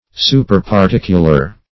Search Result for " superparticular" : The Collaborative International Dictionary of English v.0.48: Superparticular \Su`per*par*tic"u*lar\, a. [L. superparticularis.